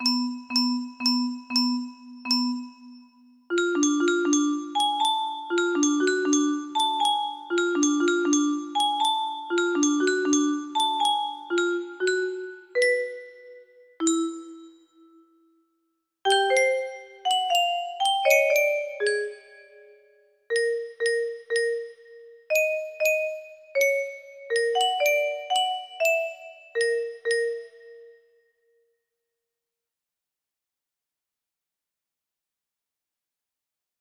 Wip music box melody